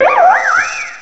cry_not_leavanny.aif